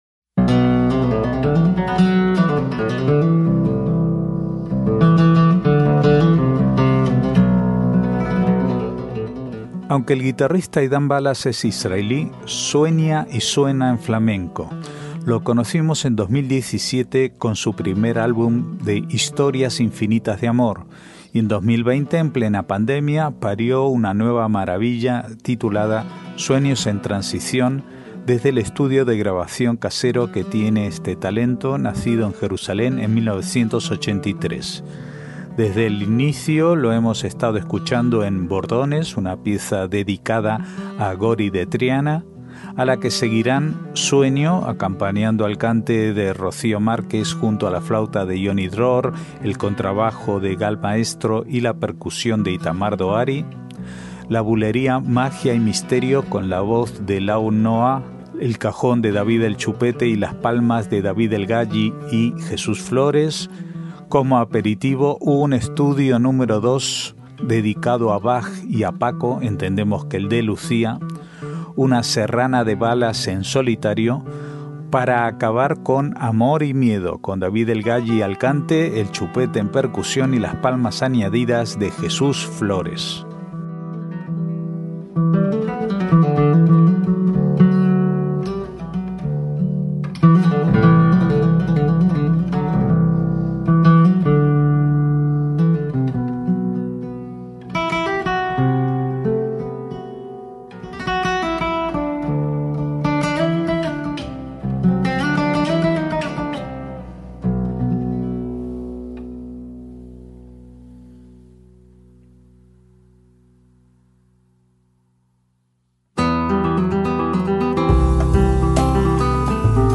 MÚSICA ISRAELÍ
sueña y suena en flamenco.
desde el estudio de grabación casero
la bulería